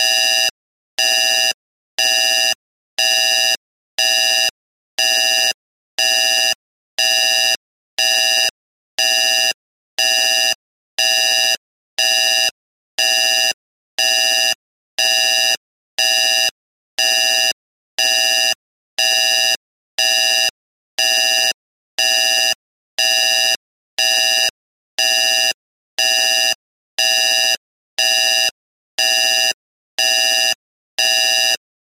science_fiction_console_alarm_011